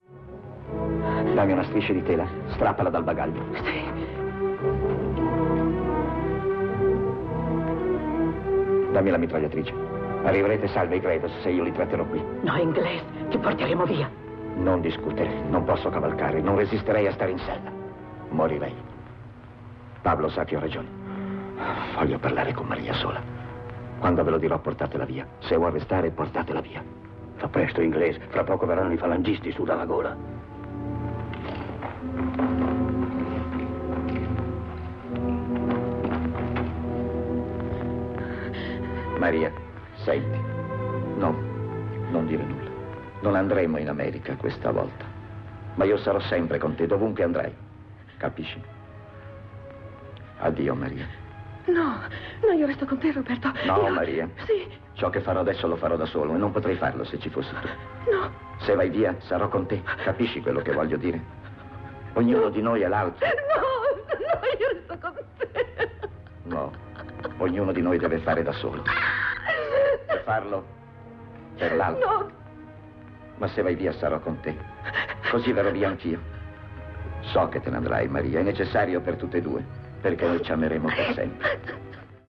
nel film "Per chi suona la campana", in cui doppia Gary Cooper.